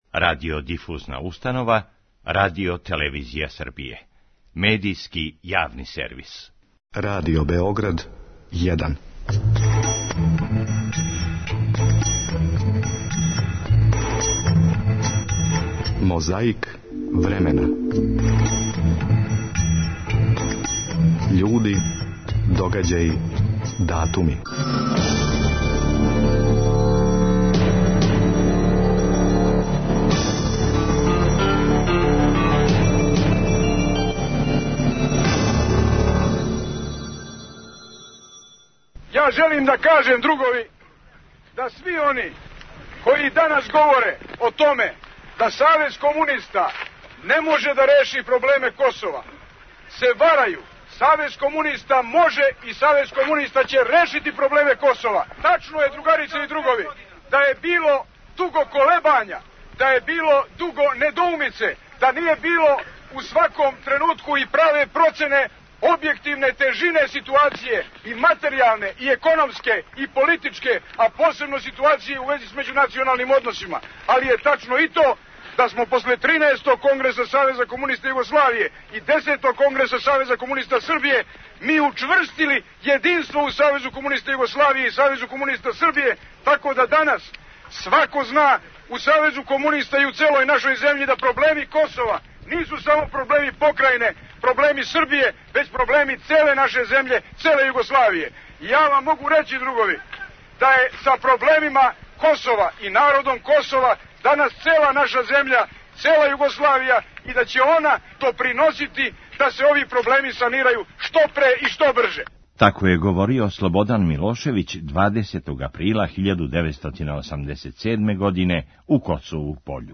Тако се збило да је јубилеј Југословенског покрета Црногораца обележен 15. априла 1999. године. Засвирале су гусле у част генерaла Драгољуба Ојданића, а једне су му спремили и за понети.